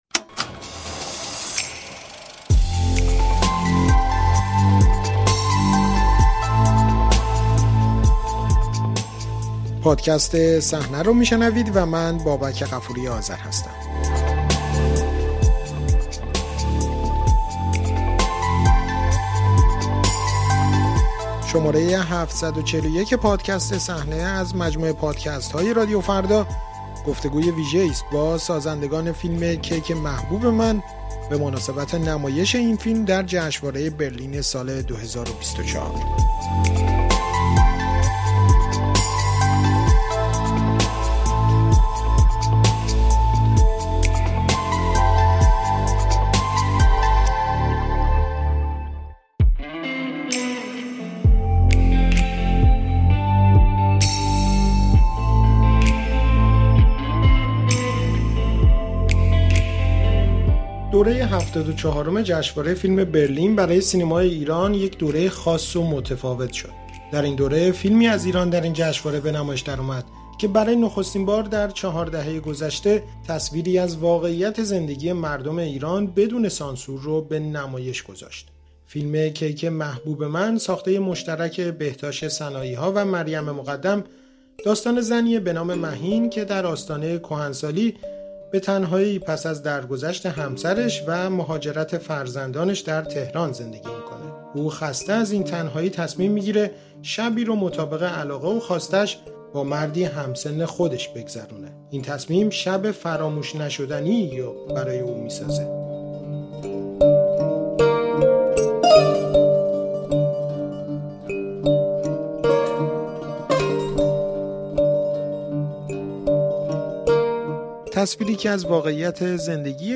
گفت‌وگو با سازندگان فیلم کیک محبوب من